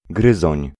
Ääntäminen
Synonyymit (kemia) corrosif grignoteur souris Ääntäminen France: IPA: [ʁɔ̃.ʒœʁ] Haettu sana löytyi näillä lähdekielillä: ranska Käännös Konteksti Ääninäyte Substantiivit 1. gryzoń {m} eläintiede Suku: m .